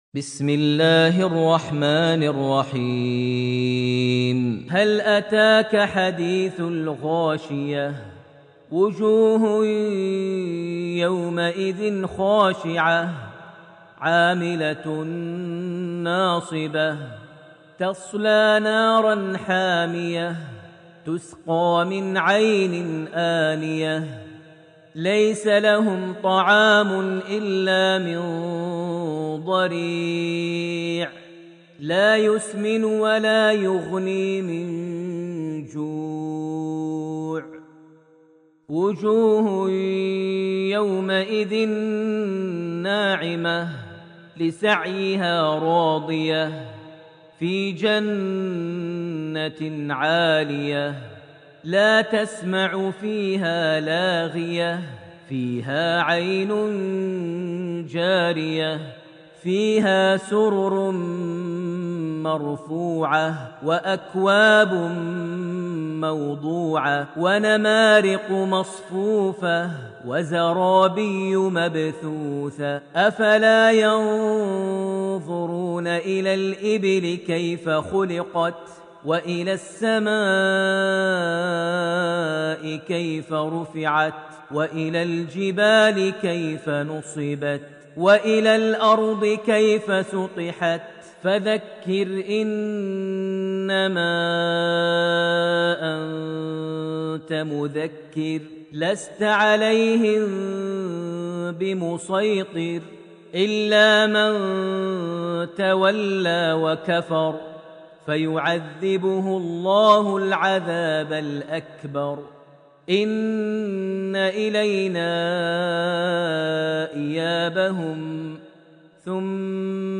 surat Al-Ghashiya > Almushaf > Mushaf - Maher Almuaiqly Recitations